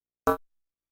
标签： midivelocity32 F4 midinote66 OberheimXpander synthesizer singlenote multisample
声道立体声